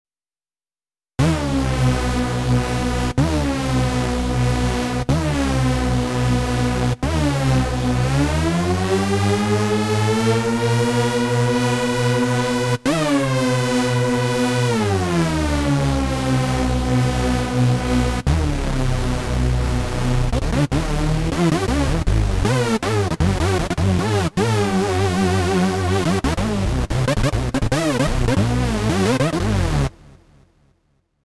Challenge: anybody managed a half decent hoover?
yeah, thanks but not really close to a proper hoover - i have got way closer